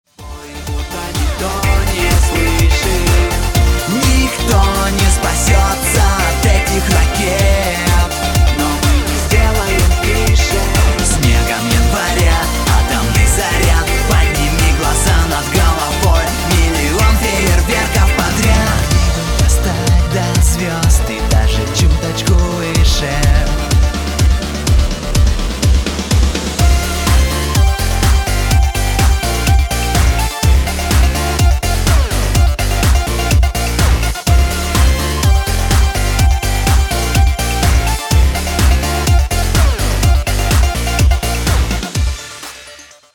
поп
ритмичные
мужской вокал
dance
Pop Rock